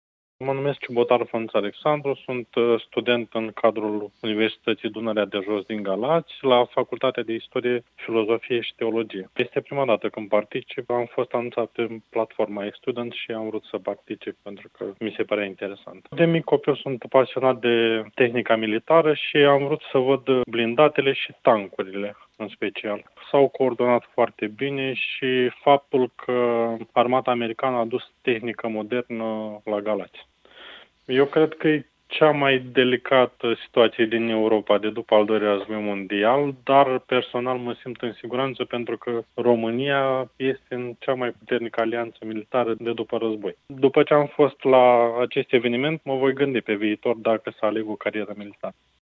Despre experiența trăită într-un poligon militar, trei dintre studenții prezenți ne-au împărtășit opinia lor despre cele văzute în poligonul Smârdan.